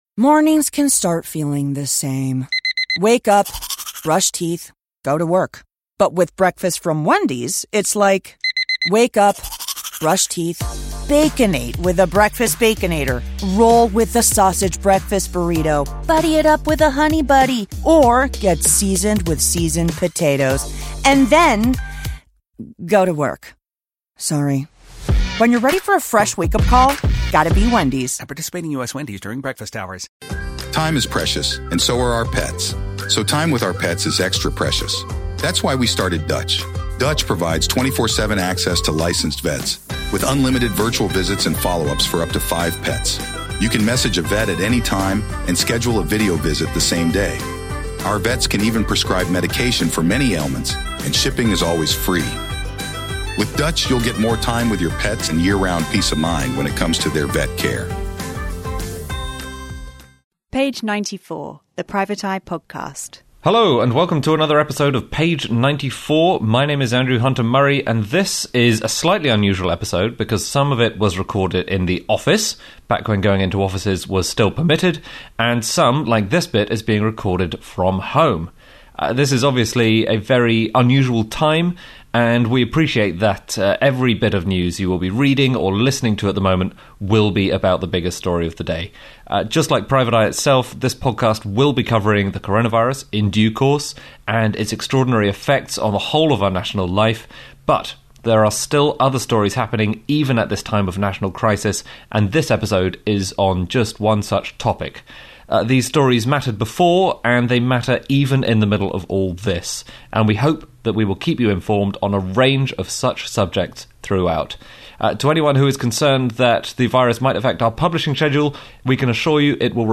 0:06.0 My name is Andrew Hunter Murray, and this is a slightly unusual episode 0:10.0 because some of it was recorded in the office,